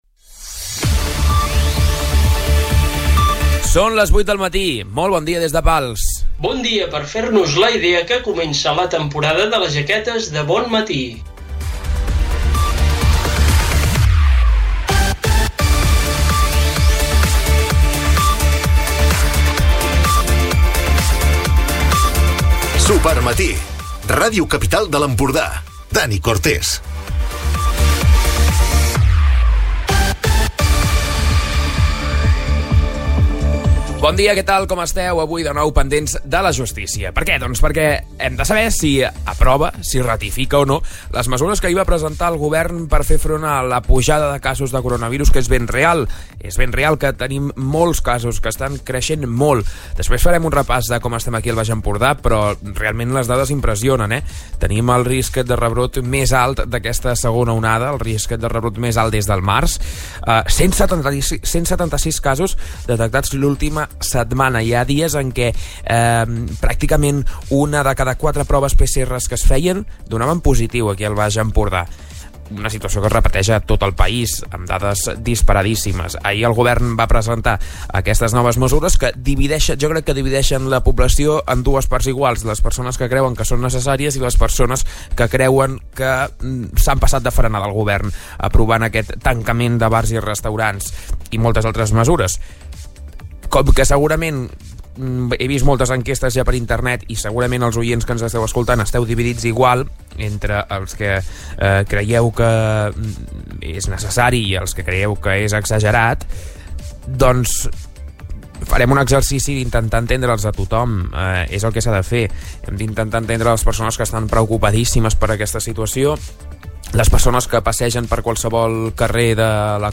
Recupera l'informatiu d'aquest dijous